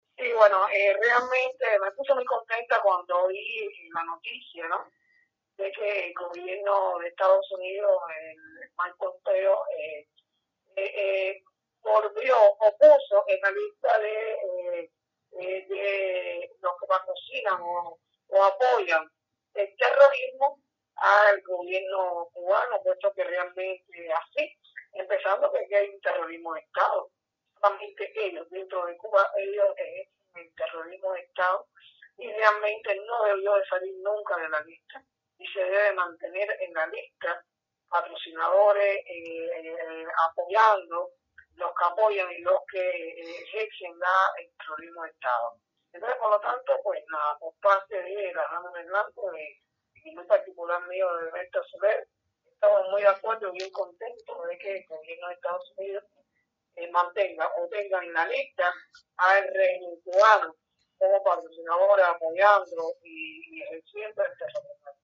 Declaraciones de Berta Soler